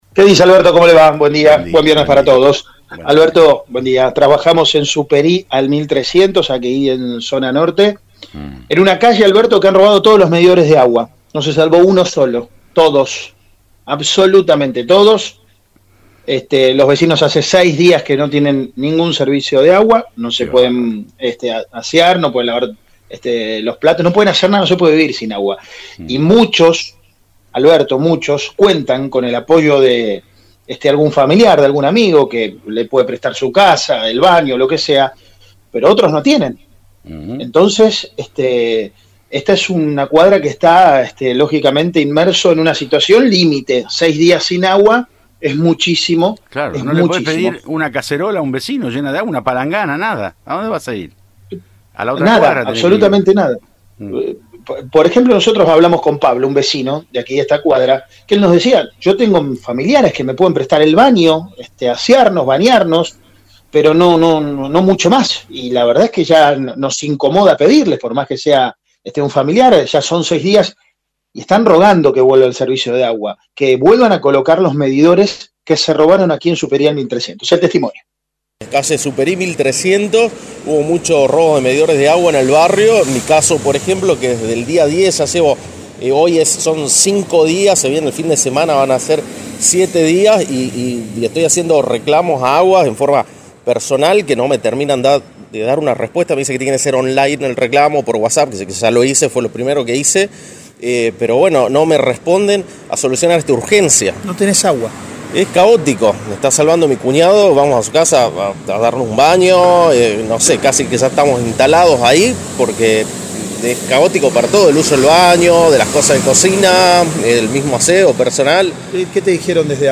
“Hubo muchos robos en el barrio. Llevo cinco días sin agua. Estoy haciendo reclamos a Aguas Santafesinas de forma personal, me dicen que tiene que ser online, pero ya lo hice. Debo solucionar esta urgencia”, comentó un vecino al móvil de Cadena 3 Rosario, en Siempre Juntos.